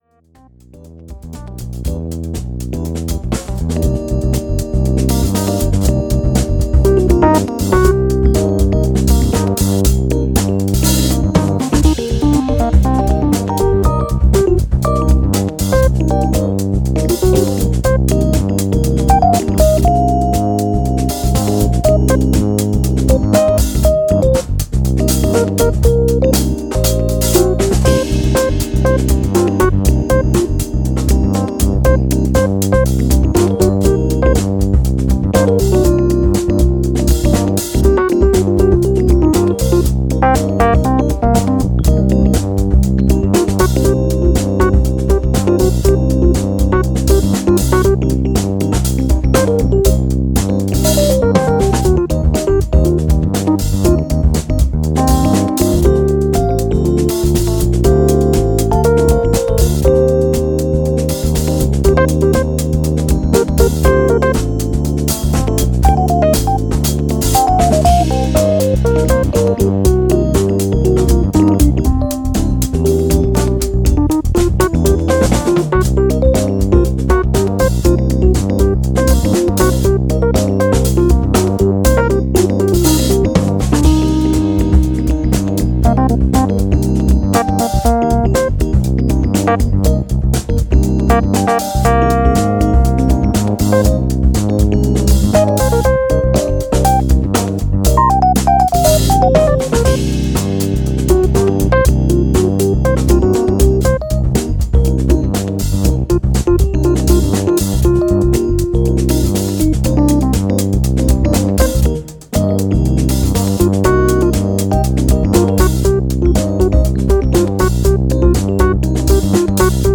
The following song is light, fun, and a little funky; adding all of those things up and the song becomes